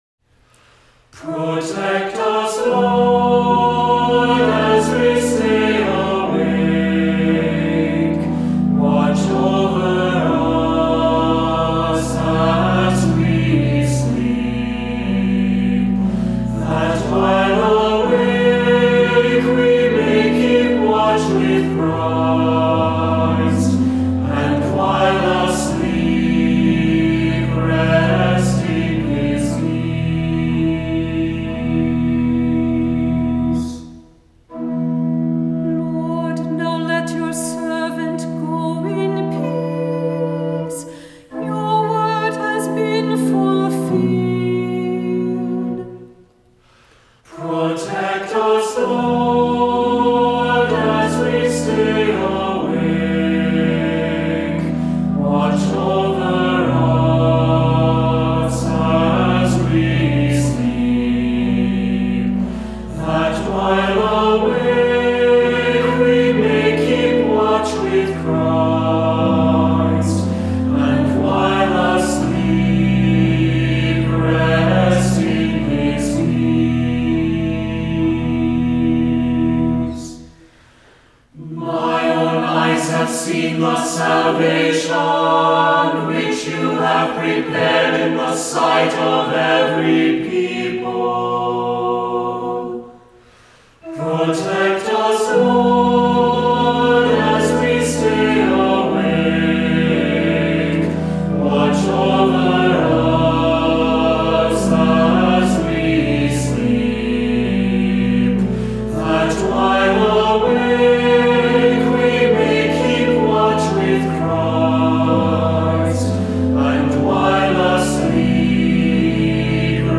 Voicing: Assembly,Cantor,SATB,Unison